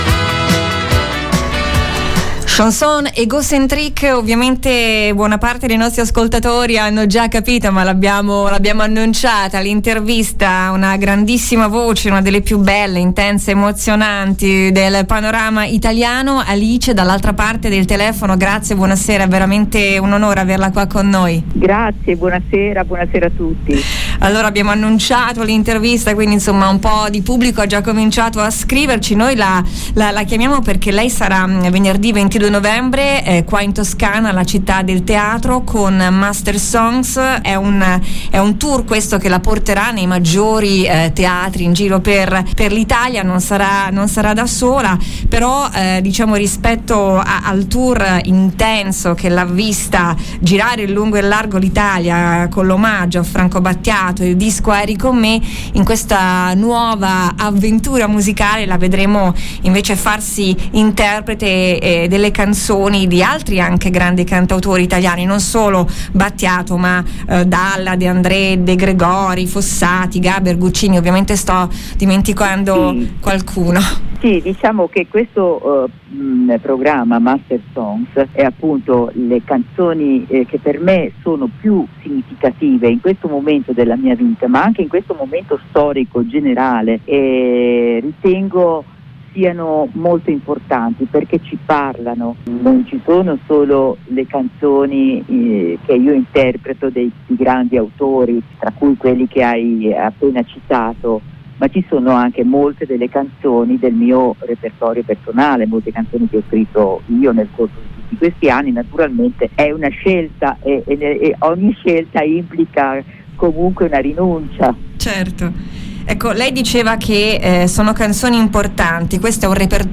Alice “Masters Songs”, l’intervista 🎧